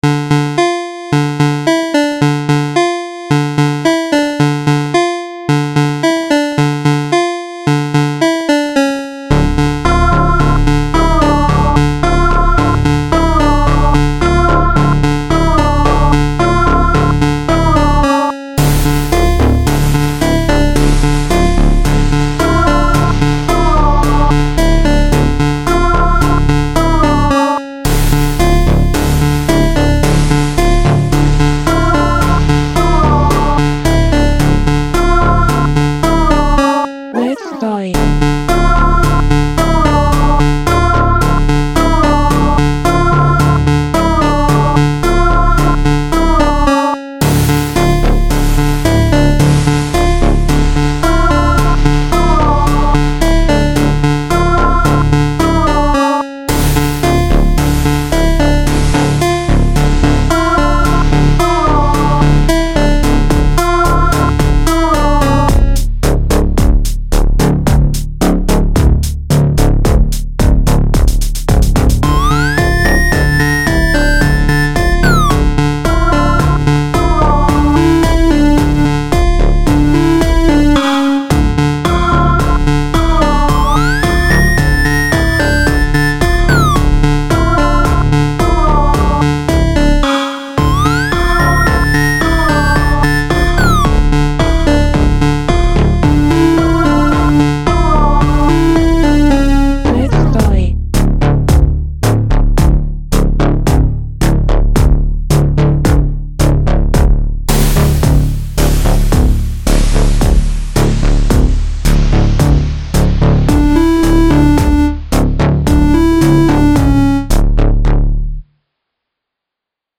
I was happy with the 16-bit ocean wave sound effect FL Studios came with, really fit well with this.
boss_music
chiptune synth synthesizer